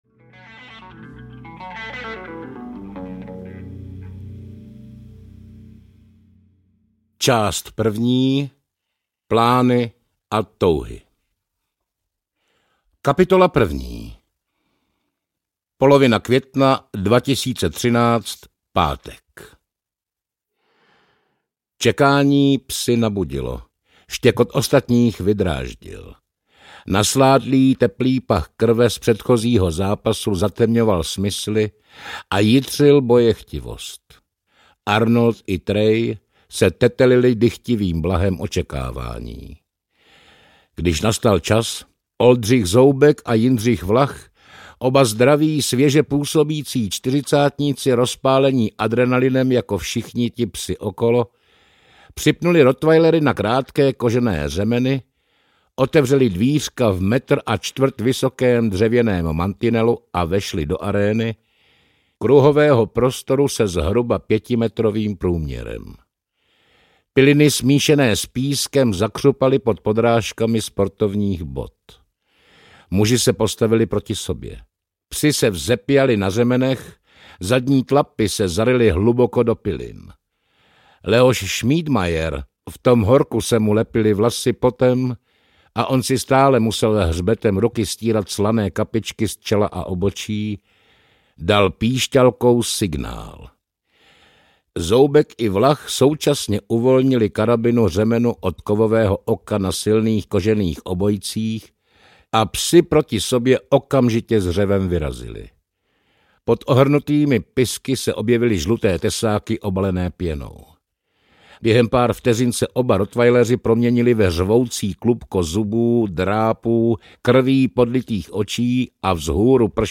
Ukázka z knihy
• InterpretNorbert Lichý
pet-mrtvych-psu-audiokniha